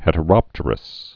(hĕtə-rŏptər-əs)